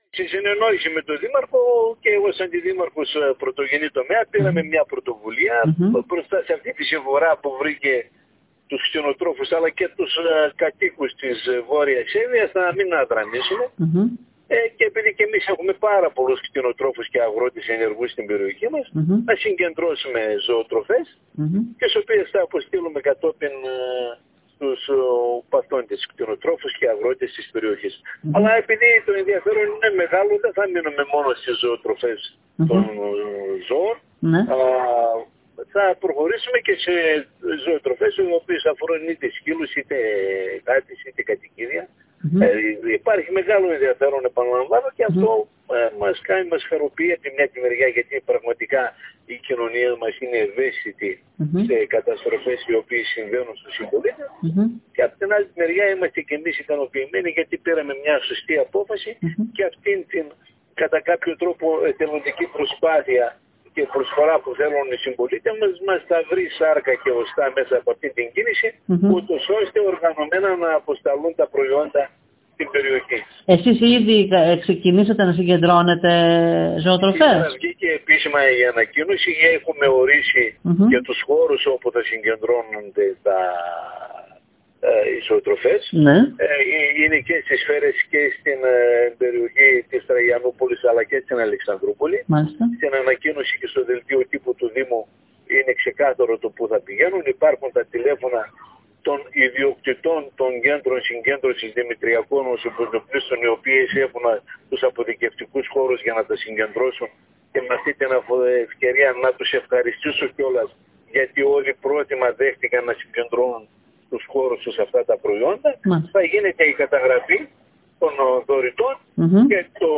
Όπως δήλωσε στην ΕΡΤ Ορεστιάδας ο κ. Κολγιώνης η συμμετοχή είναι εντυπωσιακή αφού  η περιοχή μας έχει μεγάλο αριθμό αγροτών και  κτηνοτρόφων,  στην κατοχή των οποίων υπάρχει μεγάλη επάρκεια σε ζωοτροφές.
Δημήτρης-Κολγιώνης-Αντιδημάρχος-Πρωτογενούς-Τομέα-Αλεξανδρούπολης.mp3